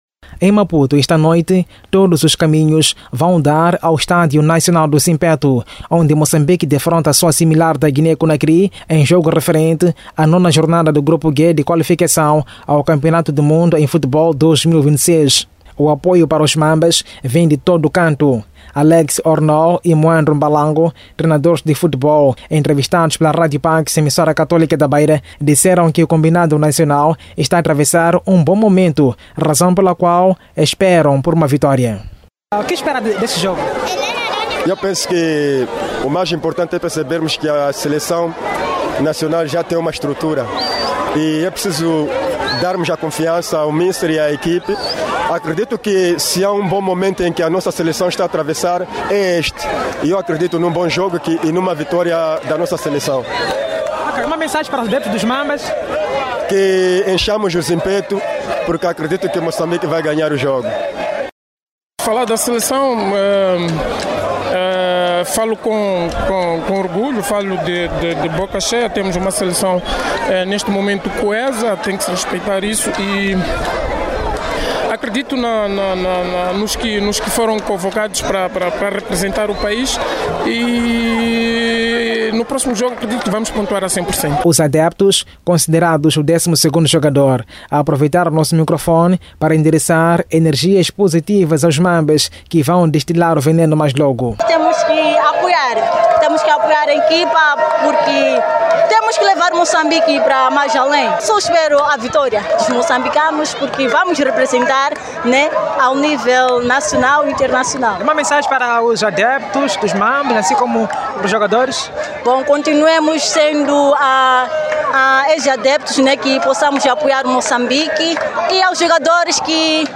Os adeptos, considerado o décimo segundo jogador, aproveitaram o nosso microfone para endereçar energias positivas aos Mambas, que vão destilar o veneno mais logo.